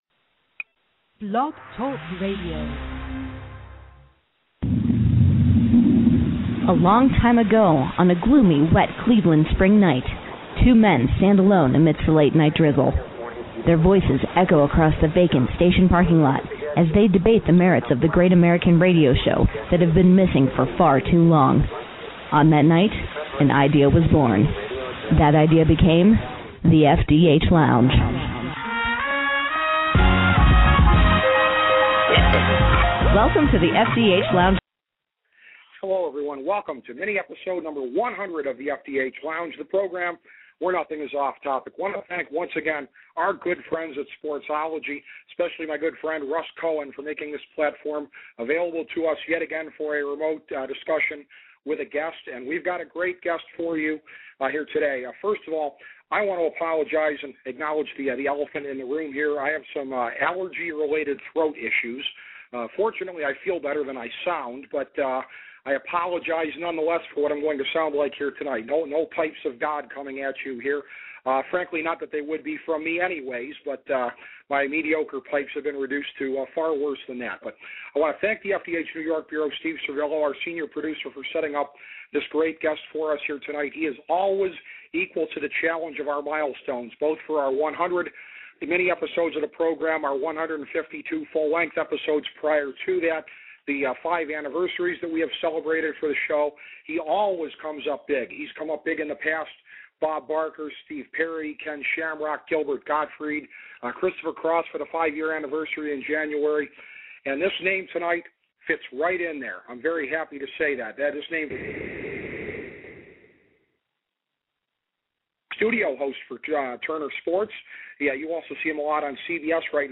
A conversation with sportscaster Ernie Johnson